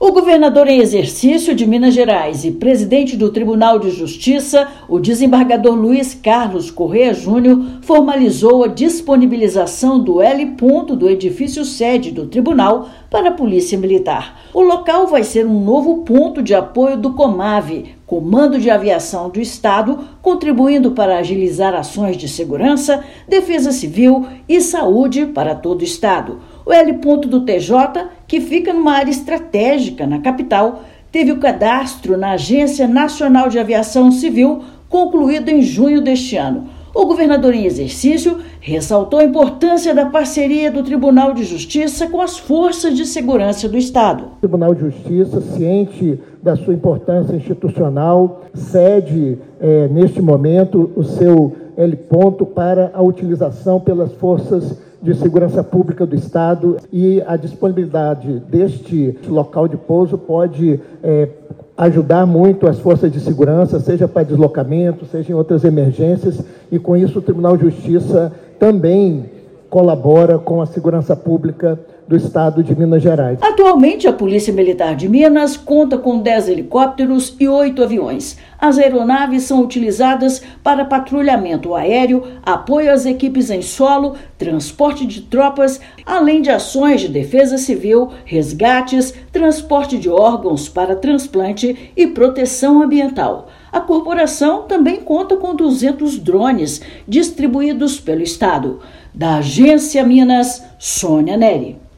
Governador em exercício formalizou parceria em solenidade na Cidade Administrativa; espaço fica no edifício-sede do TJMG e vai contribuir para agilizar ações de segurança, defesa civil e saúde para todo o estado. Ouça matéria de rádio.